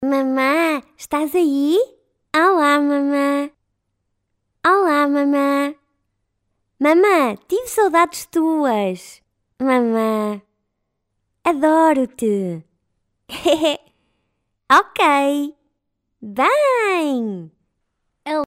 locutora infantil